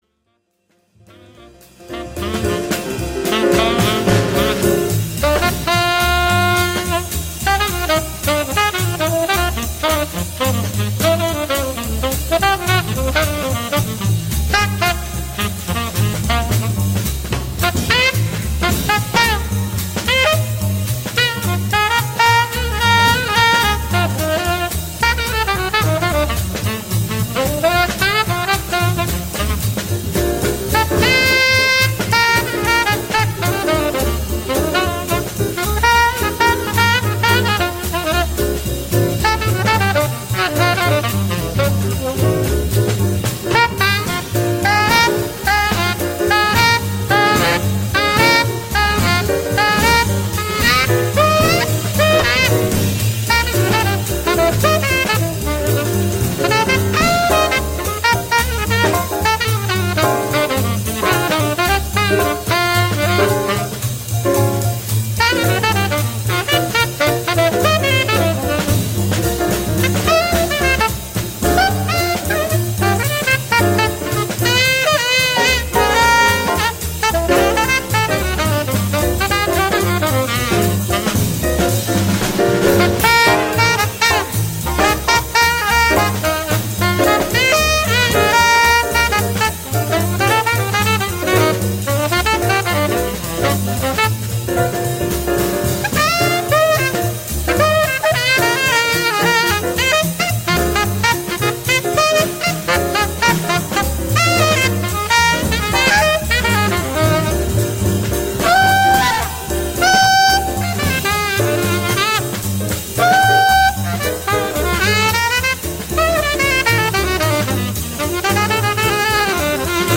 jazz quartet
The pitches are secondary to placement.